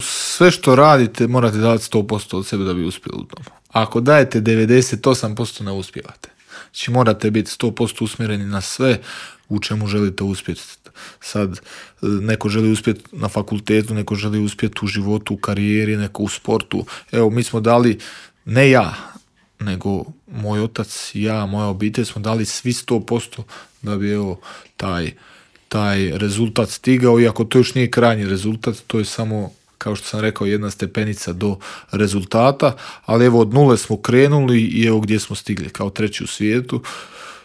O svom putu do svjetske bronce govorio je naš najbolji boksač u Intervjuu Media servisa.